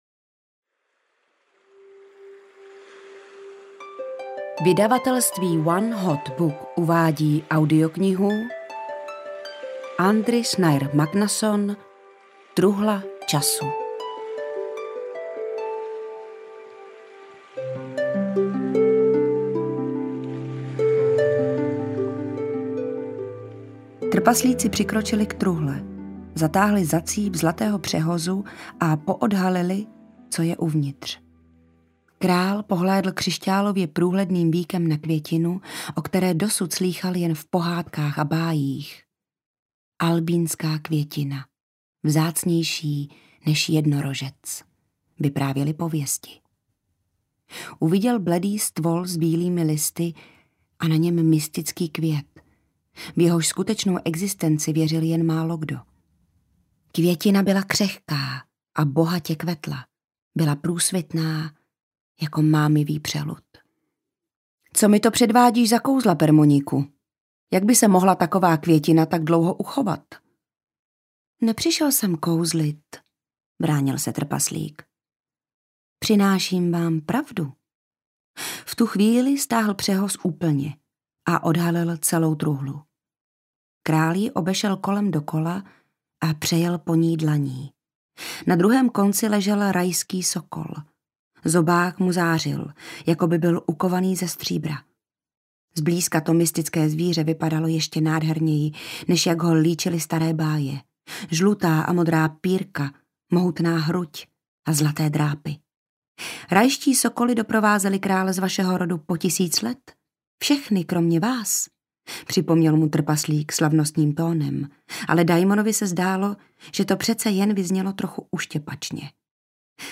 Truhla času audiokniha
Ukázka z knihy
truhla-casu-audiokniha